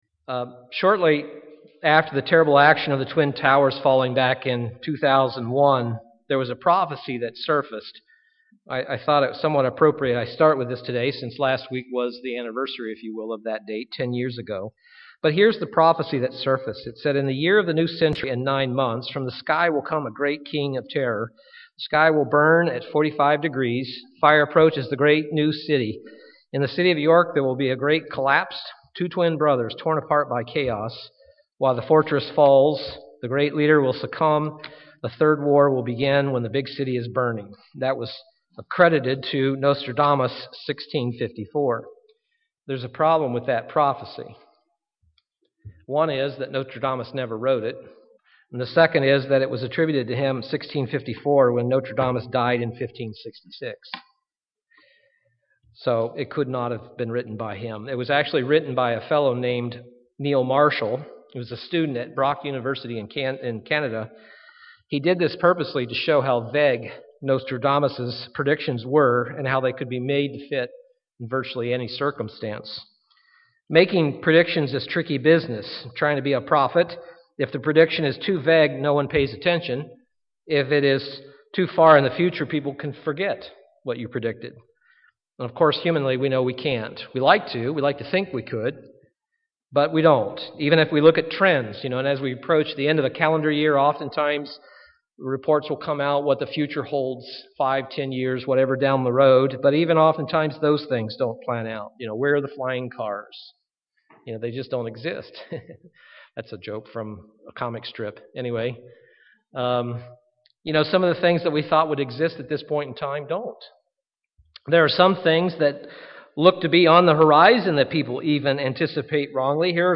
This sermon will take a look at 5 points regarding prophecy so that we have a proper perspective of prophecy from God’s Word.
Given in Milwaukee, WI